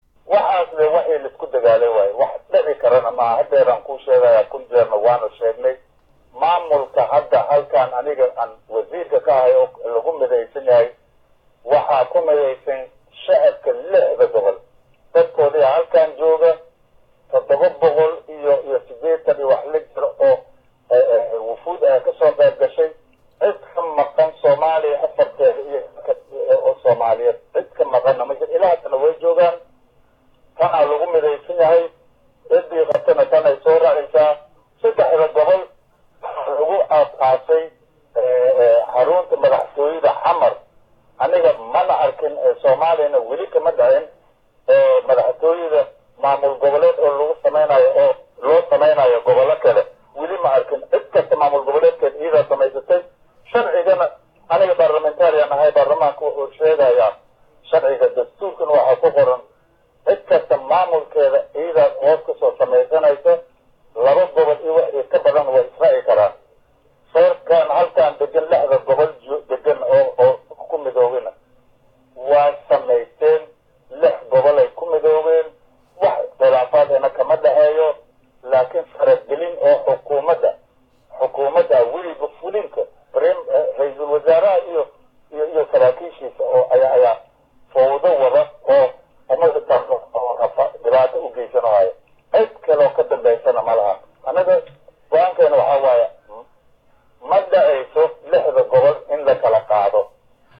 leestoWasiirka wasaarada Gaadiidka iyo garoomada ee dowlad goboleedka koonfur galbeed C/llaahi Maxamed Idiris Leesto  asagoo u waramayey warbaahinta ayaa waxaa uu si adag uga hadley in maamulka 6da gobol ey ku mideysan yihiin dhamaan dadka degen lexda gobol aana la kala goyn karin.